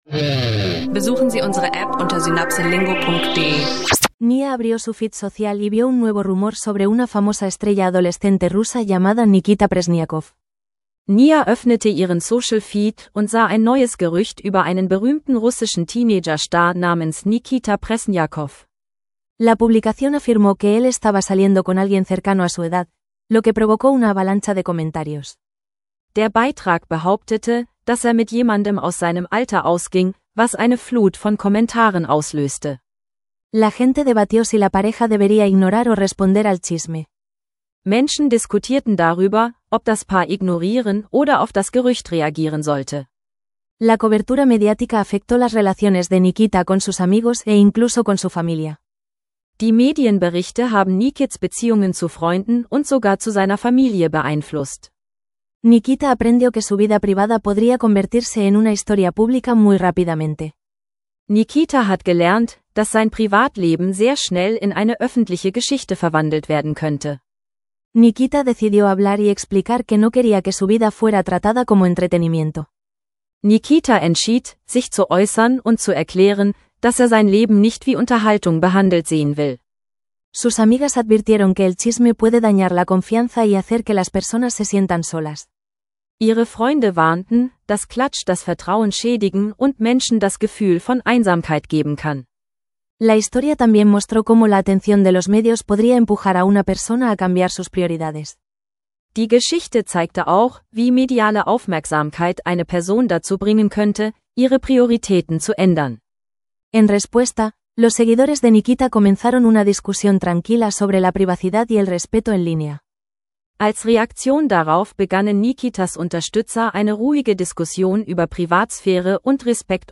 Zwei Geschichten über Promi-Gerüchte und professionelles E-Sport-Drama – lerne Spanisch mit realen Gesprächen und Diskussionen.